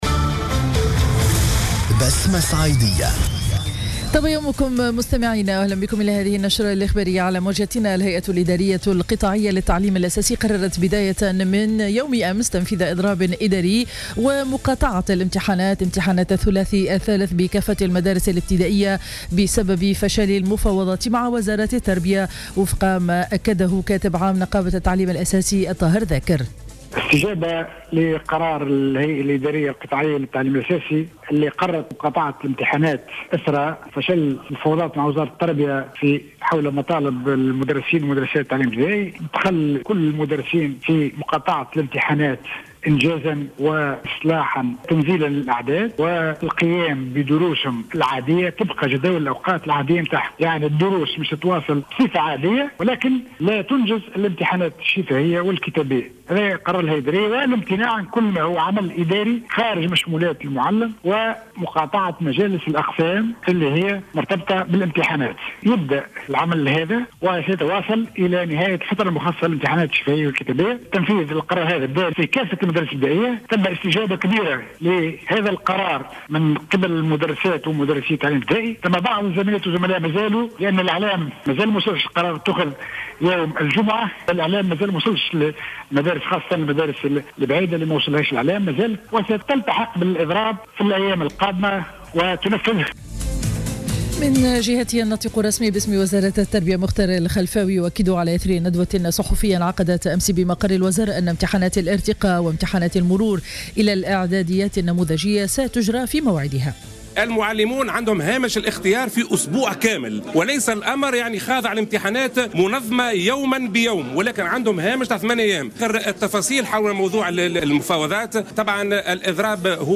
نشرة أخبار السابعة صباحا ليوم الثلاثاء 02 جوان 2015